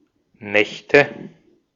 Ääntäminen
Ääntäminen Tuntematon aksentti: IPA: /ˈnɛçtə/ Haettu sana löytyi näillä lähdekielillä: saksa Käännöksiä ei löytynyt valitulle kohdekielelle. Nächte on sanan Nacht monikko.